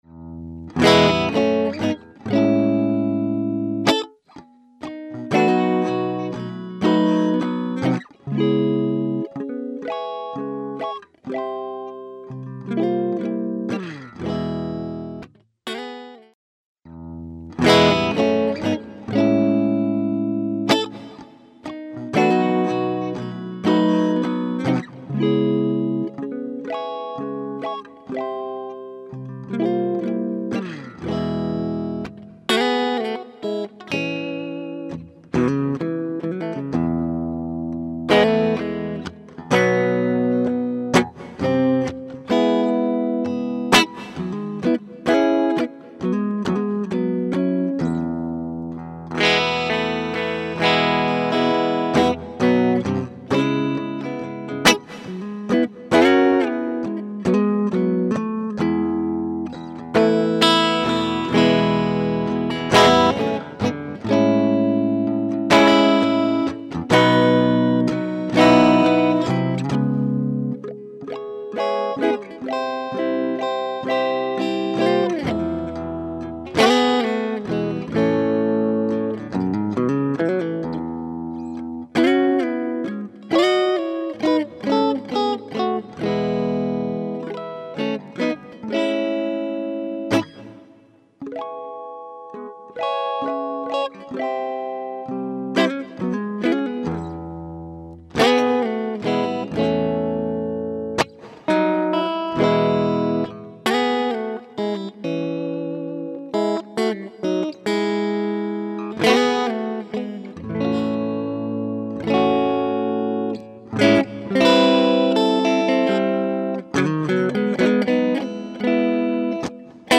The following audio clips used an indentical basic setup to show the capabilities and quality that can be obtained by using the AMP+.
The guitar was plugged straight into the Super 57 or M59 AMP+ and then routed into a Focusrite Saffire 6 USB Interface.
Some of the clips have a dry section 1st and then with added post production reverb to show what a typical studio track might sound like.